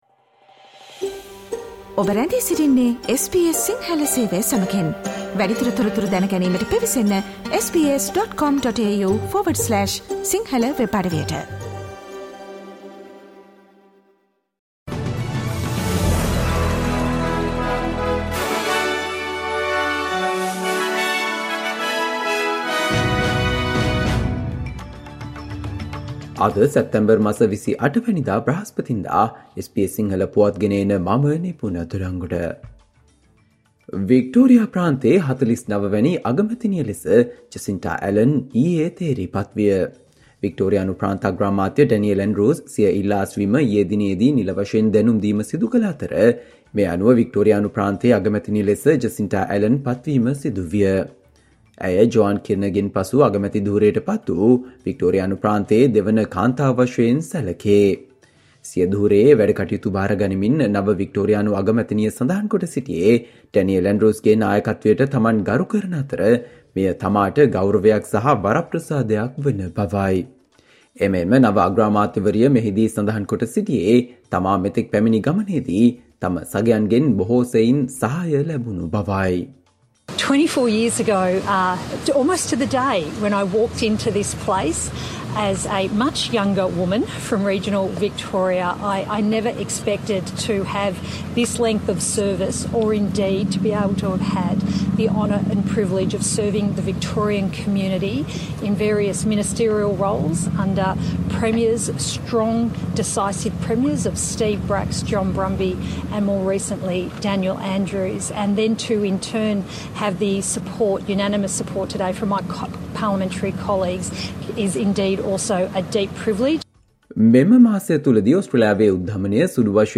Australia news in Sinhala, foreign and sports news in brief - listen, today - Thursday 28 September 2023 SBS Radio News